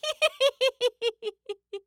horror
Witch Female Laugh